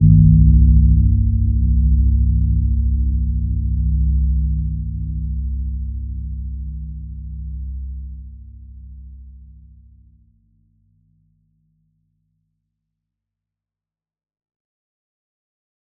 Gentle-Metallic-2-C2-mf.wav